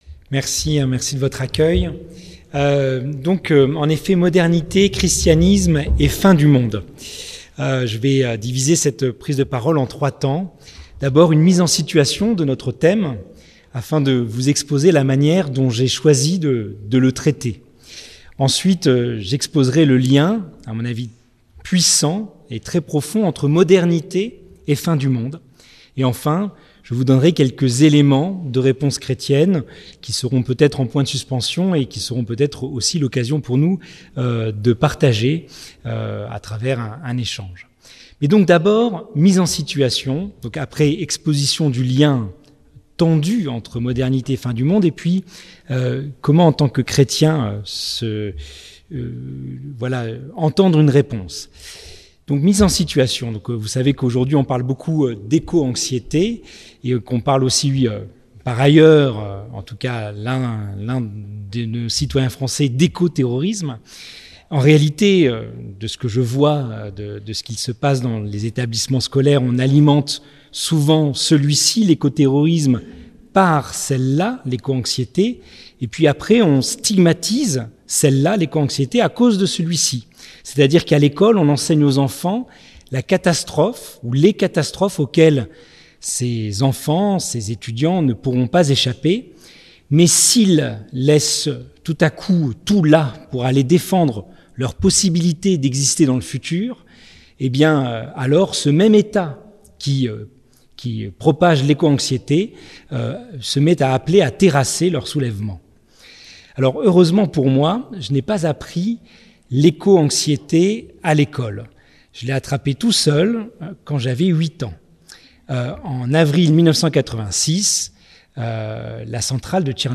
Ste Baume - Université d'été 2023